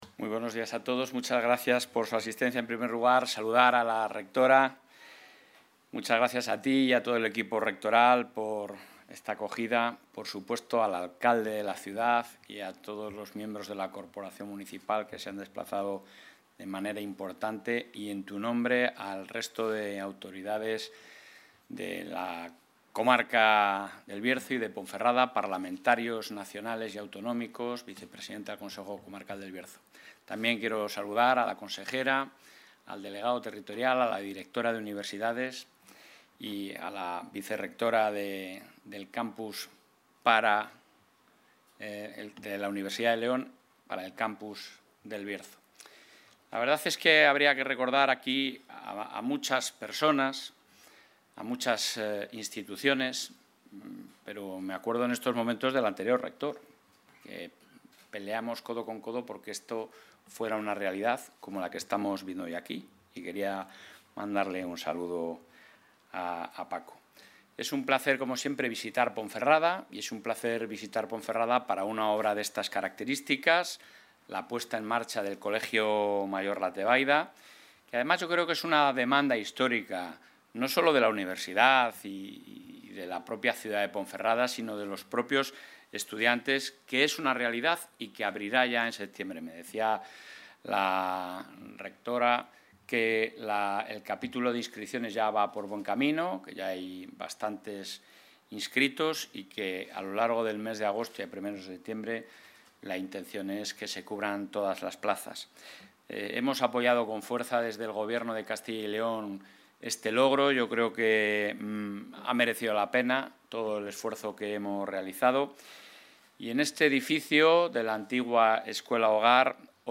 Intervención de la vicepresidenta de la Junta.